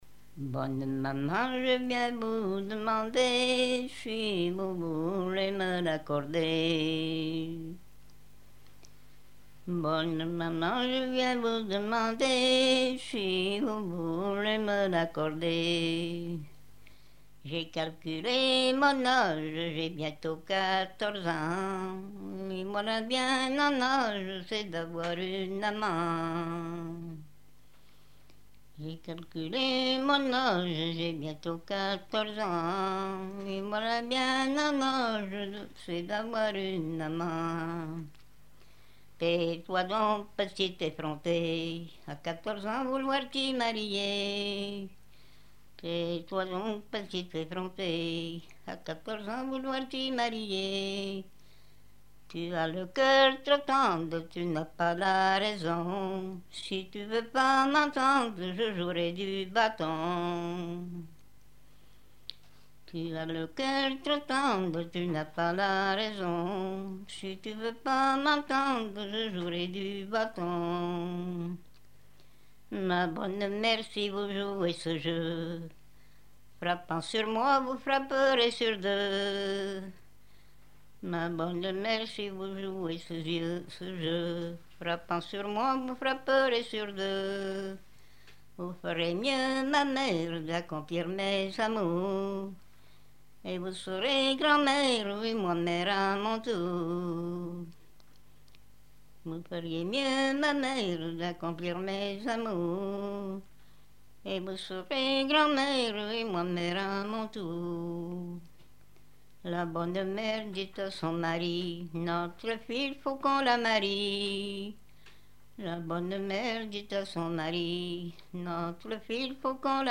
Genre dialogue
Catégorie Pièce musicale inédite